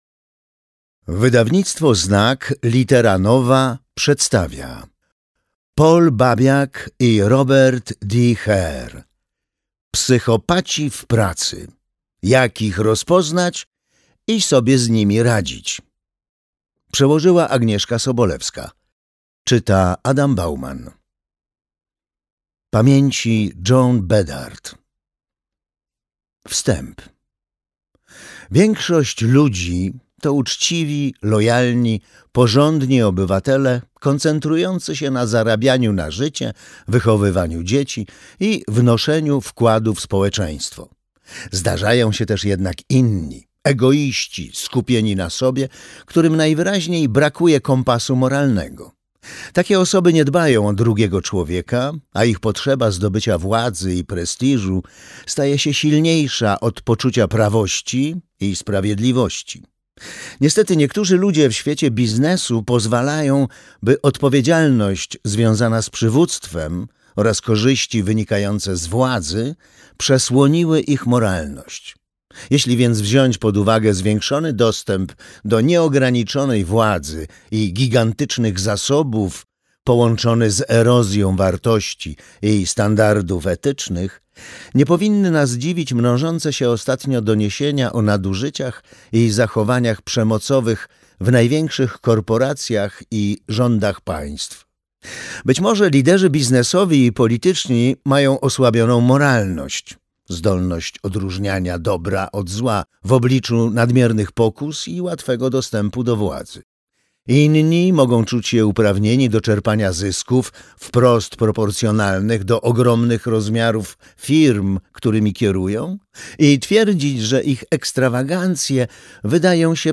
Psychopaci w pracy. Jak ich rozpoznać i sobie z nimi radzić - Paul Babiak, Robert D. Hare - audiobook